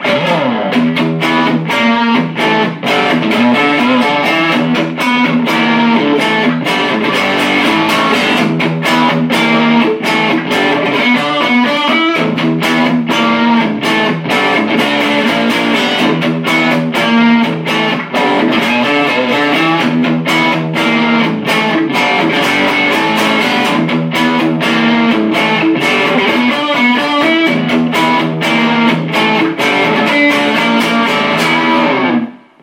P90 Full Vol Rock